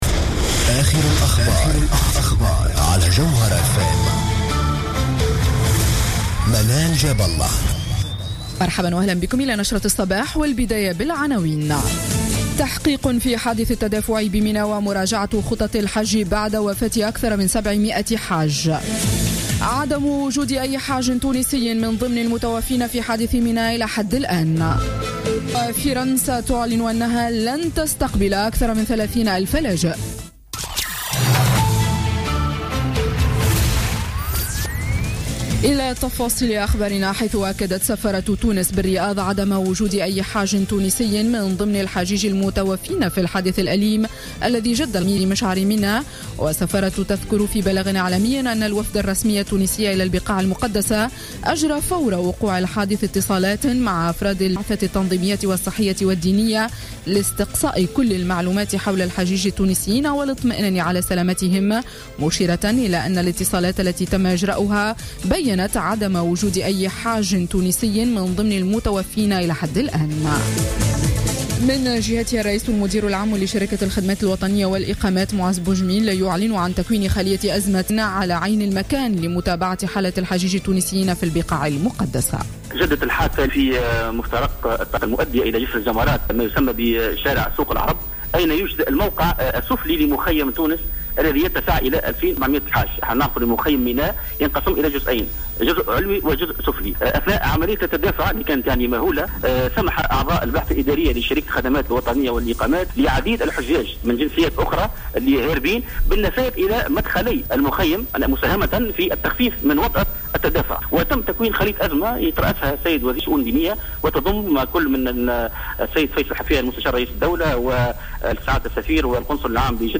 Journal Info 7h00 du vendredi 25 septembre 2015